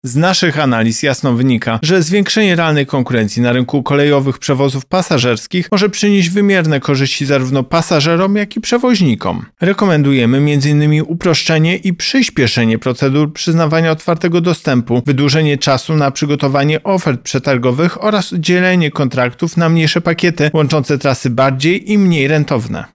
Tomasz Chróstny -mówi Tomasz Chróstny, prezes Urzędu Ochrony Konkurencji i Konsumentów.